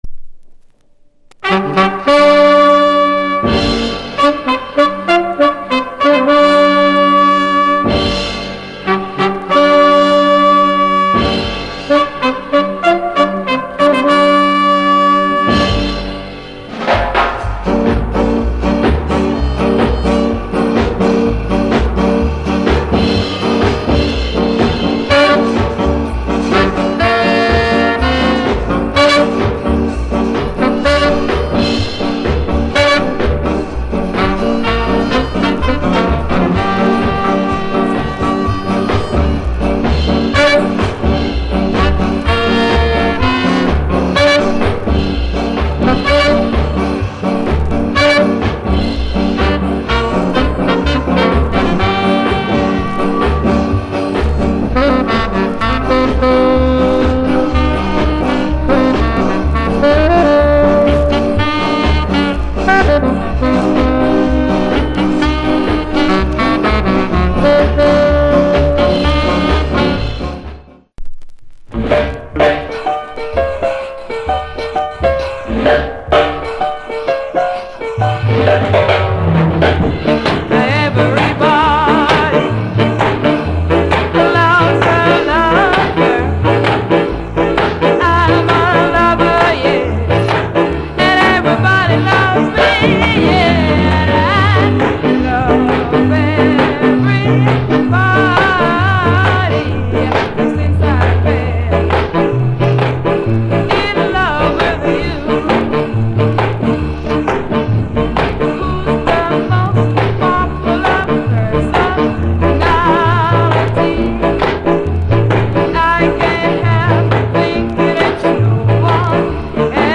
Production Genre Ska
Female Vocal Condition EX Soundclip